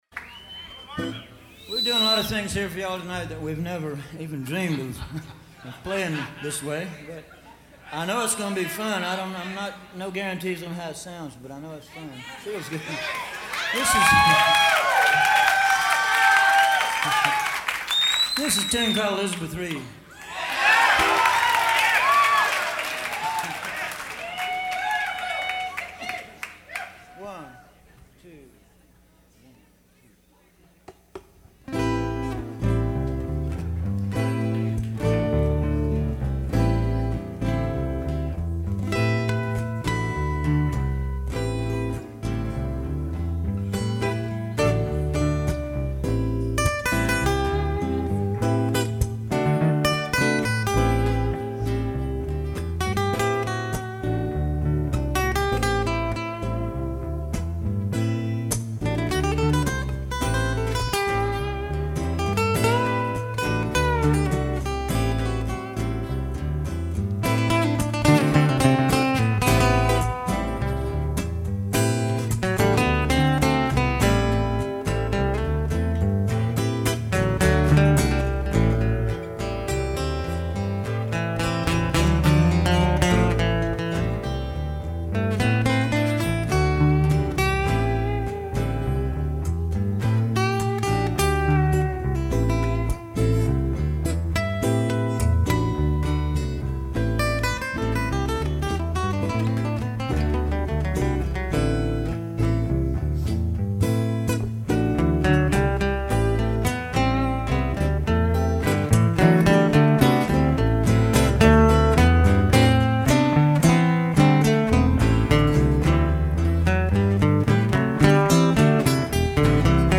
National steel guitar